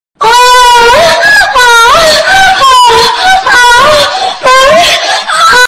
ngakak.mp3